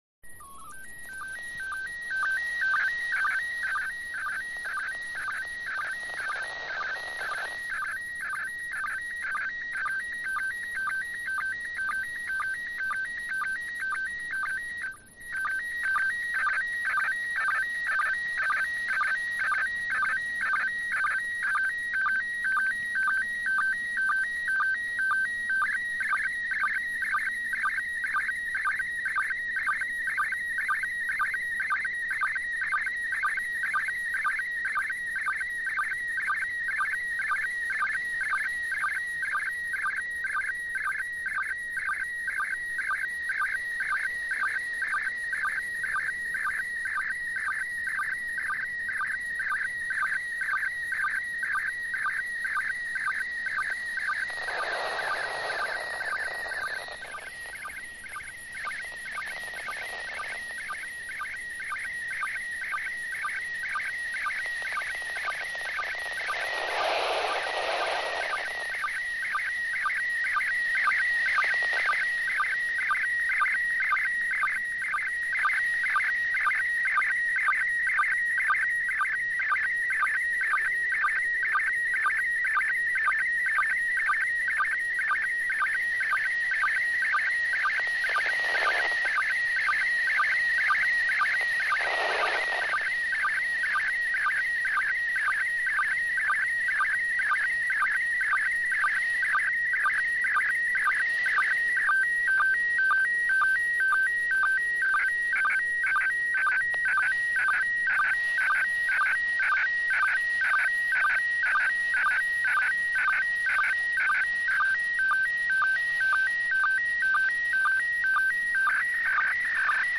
I included the sound files for each image so you can hear the SSTV tones that were transmitted.